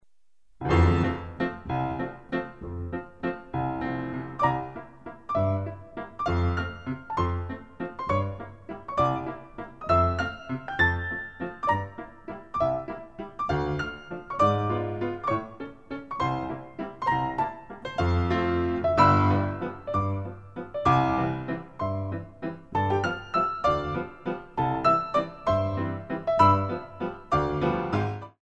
Traditional and Original Compositions for Ballet Class
Performed on a Steinway in Finetune Music / Los Angeles